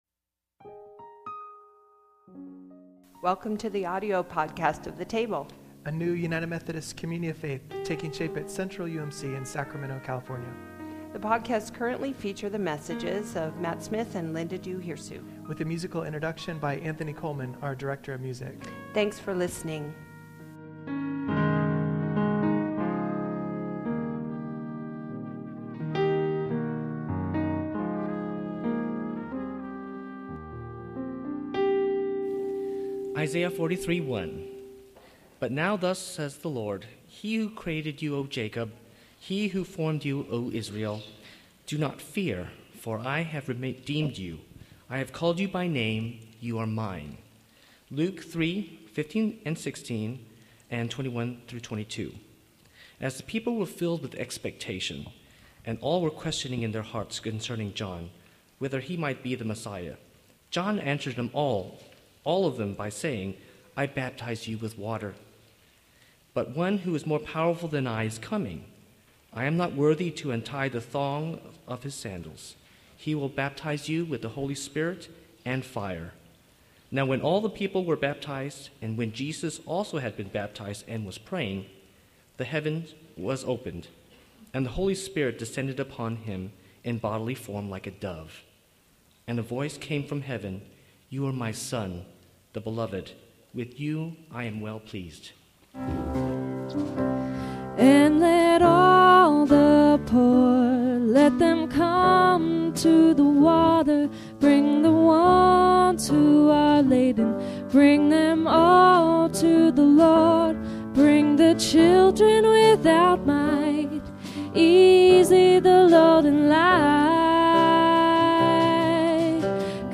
invites reflection on the meaning and significance of baptism as we gathered to celebrate the baptism of 5 people in worship.